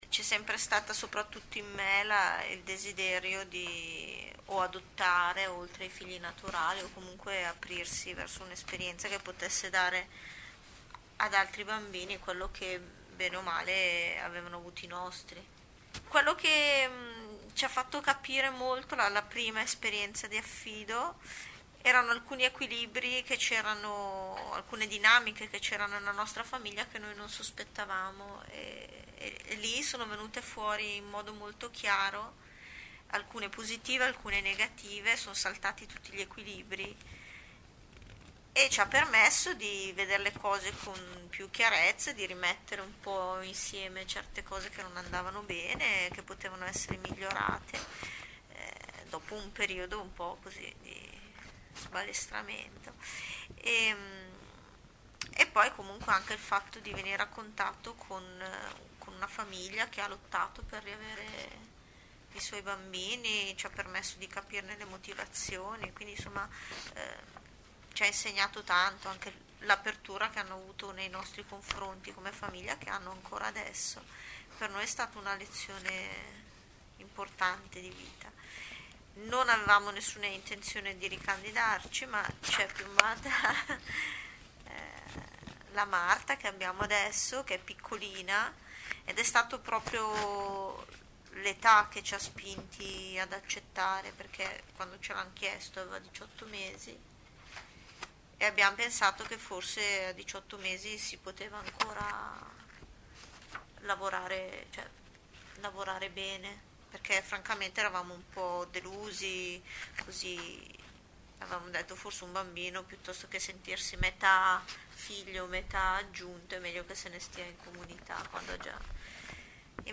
- Testimonianze audio di famiglie affidatarie: 1  |
testimonianza_affido_2.mp3